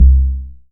GRASS BASS 1.wav